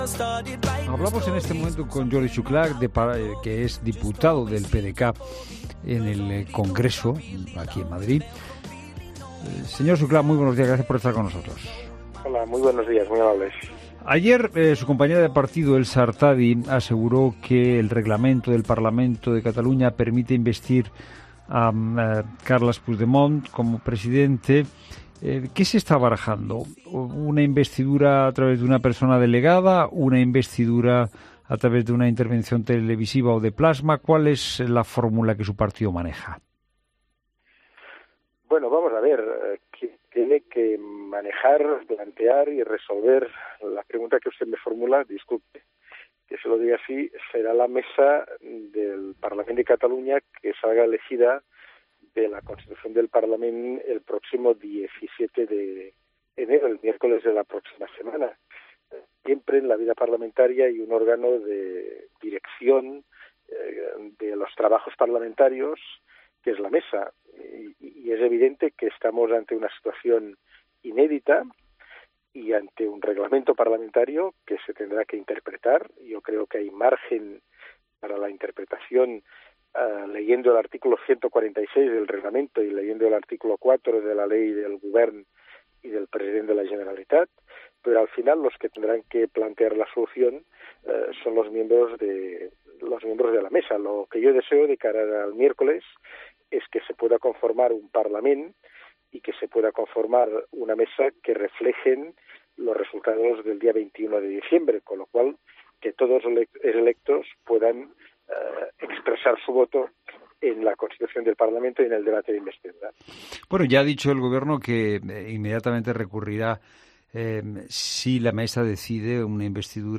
Entrevista política
Jordi Xuclà, coordinador de los diputados y senadores del PDeCAT en Madrid, en una entrevista a Fernando de Haro considera que ha sido muy mala la aplicación del 155 para la administración.